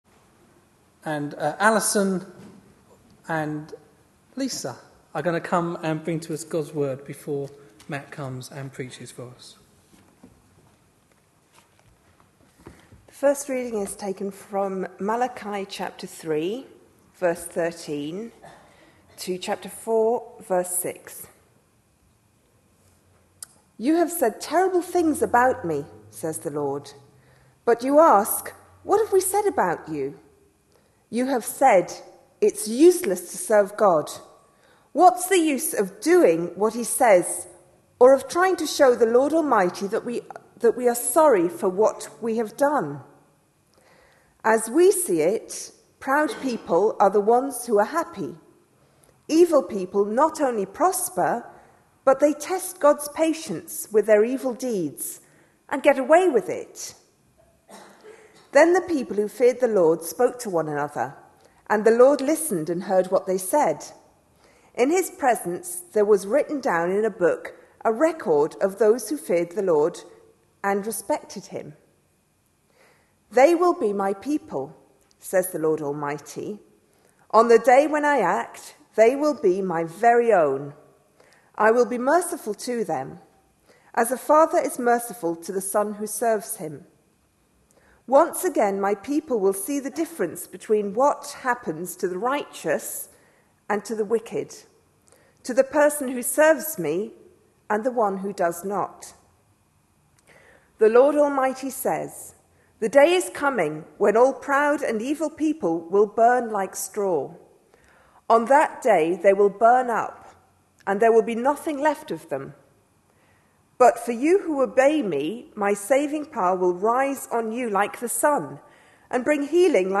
A sermon preached on 20th November, 2011, as part of our Malachi (Sunday evenings). series.